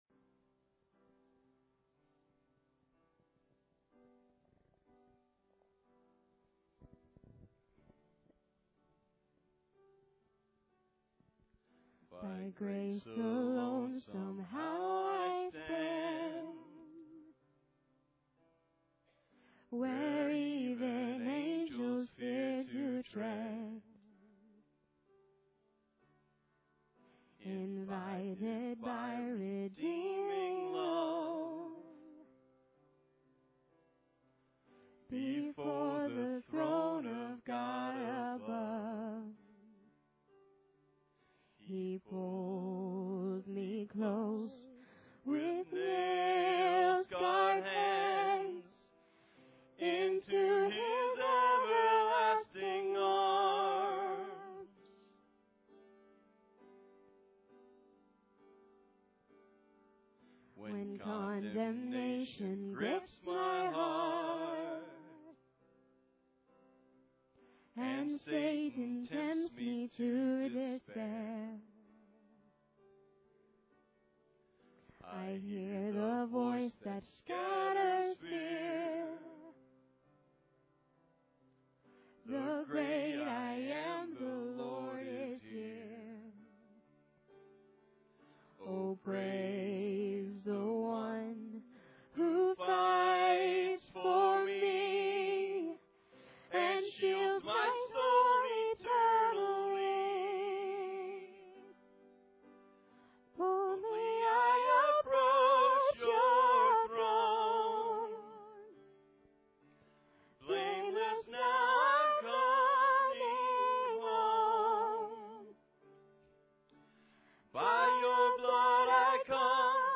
Colossians 1:9-12 Service Type: Sunday Service Introduction I. The determining cause